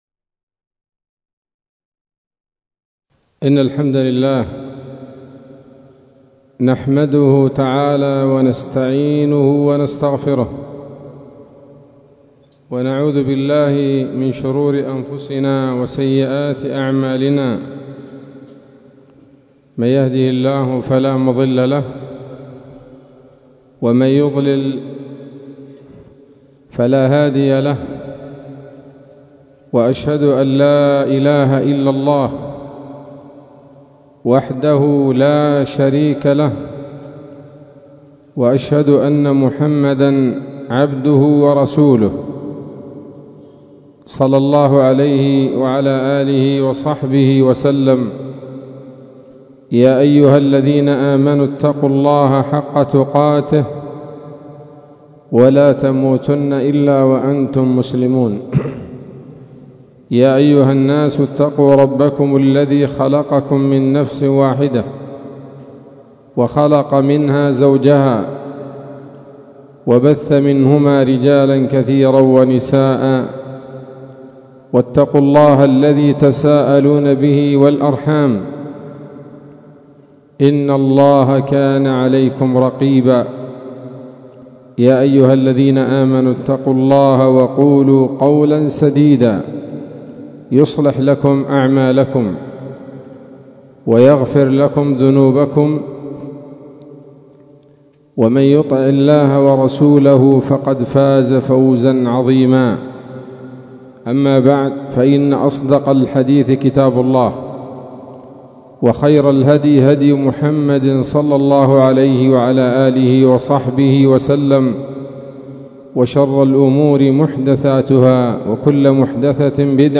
محاضرة قيمة بعنوان: (( ‌وسطية الإسلام )) ليلة السبت 1 صفر 1447هـ، مسجد الجماهير - محافظة تعز